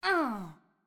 SFX_Mavka_Hit_Voice_05.wav